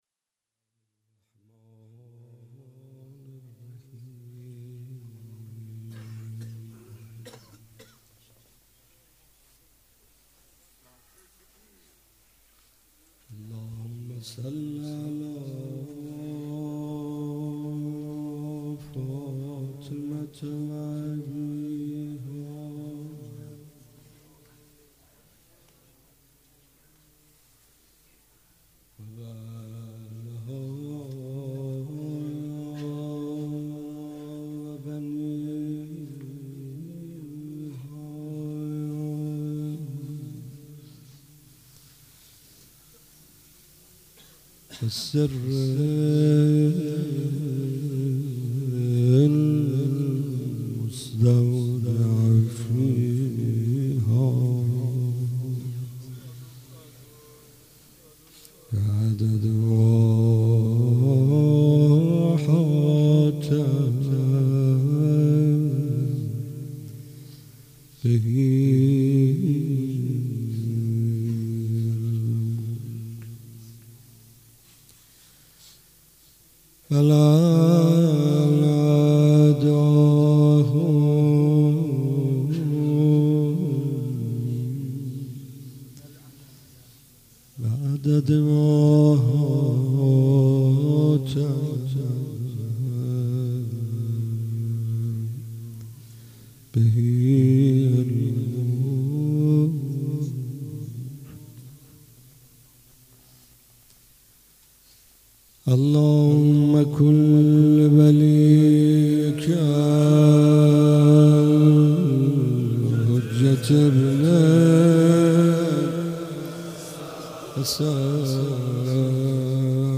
فاطمیه 95 - شب چهارم - مناجات - امان ز جمعه امروز